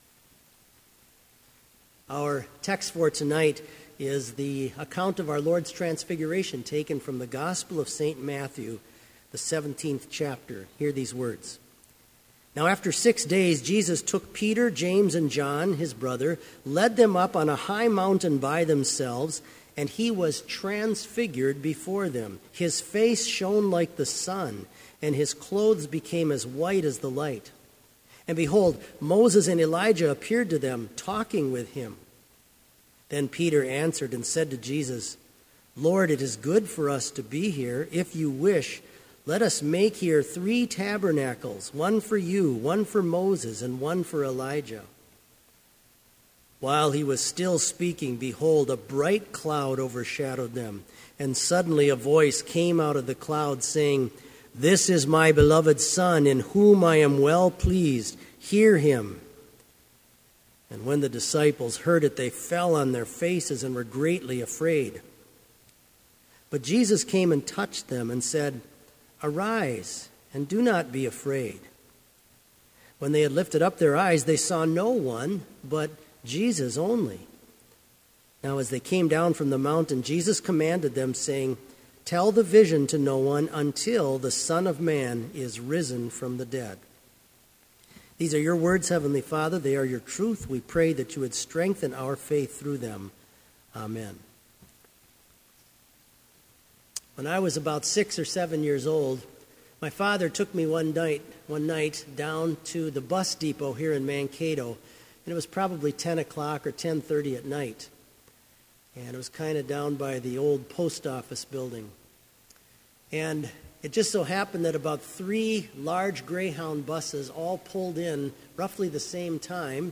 Sermon audio for Evening Vespers - January 20, 2016